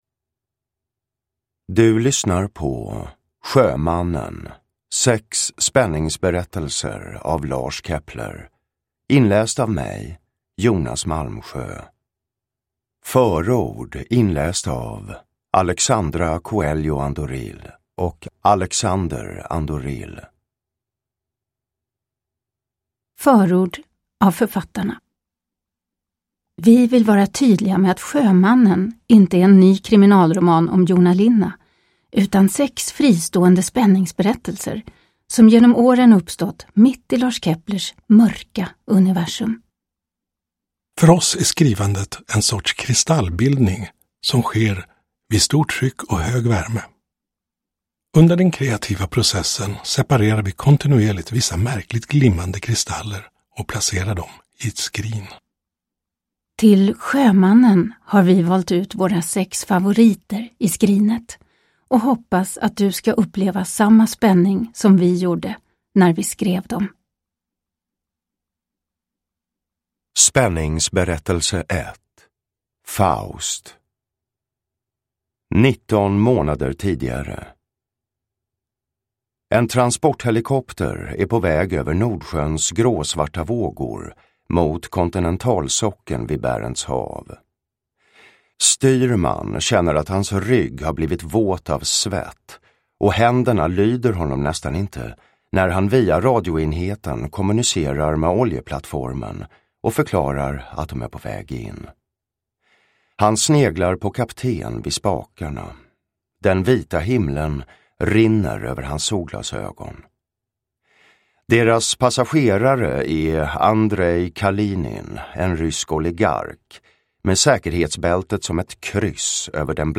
Sjömannen : Spänningsberättelser – Ljudbok
Uppläsare: Jonas Malmsjö